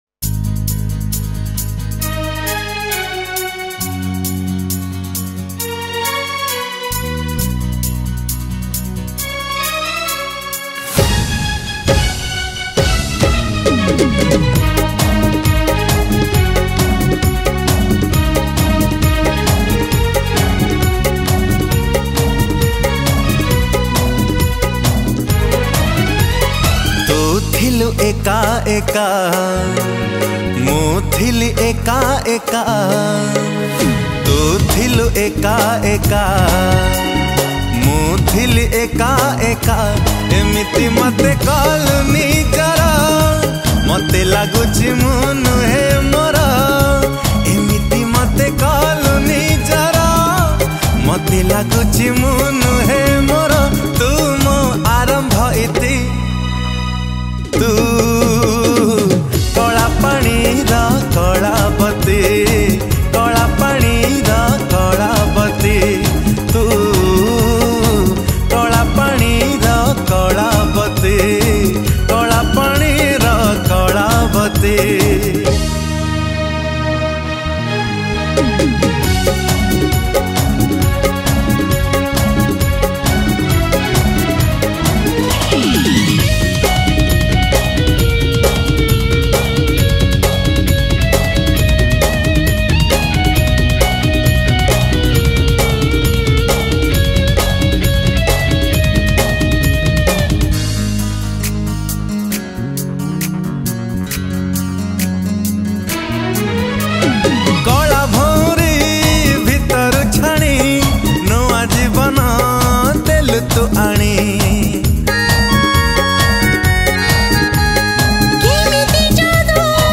Odia Jatra Song Songs Download